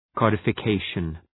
Προφορά
{,kɒdəfə’keıʃən}